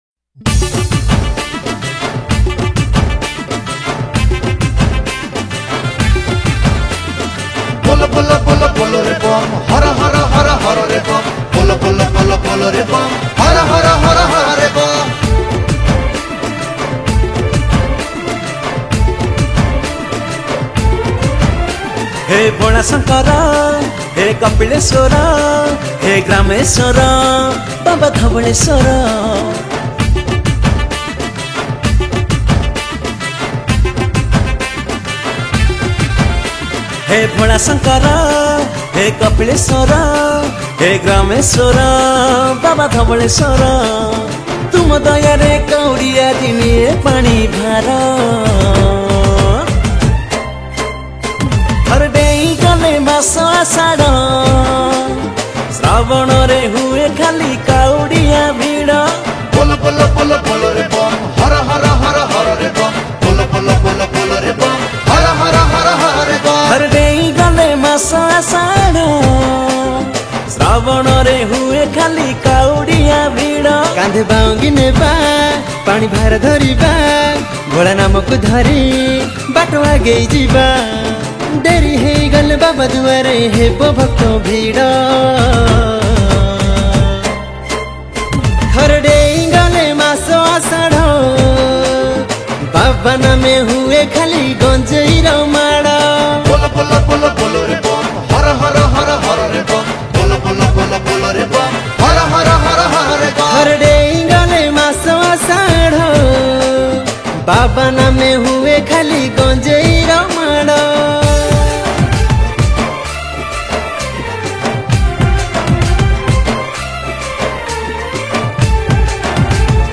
Bolbum Special Song